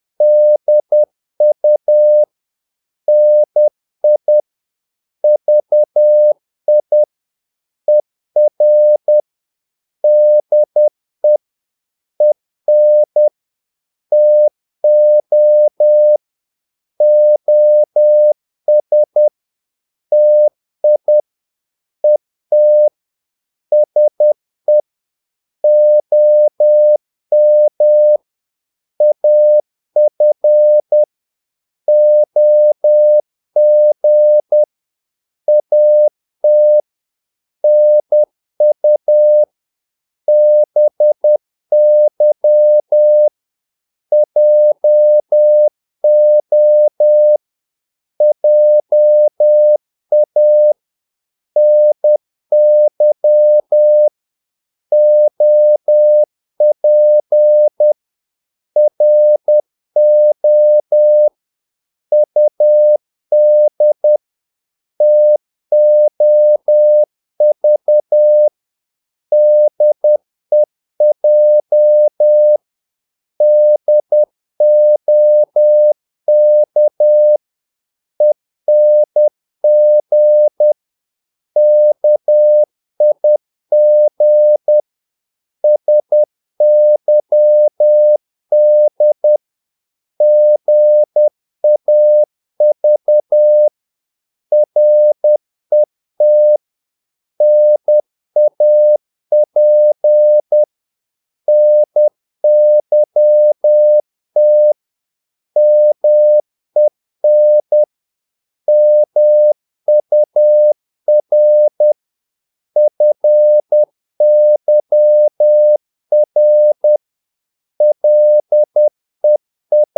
Korte danske ord 10wpm | CW med Gnister
Korte ord DK 10wpm_0.mp3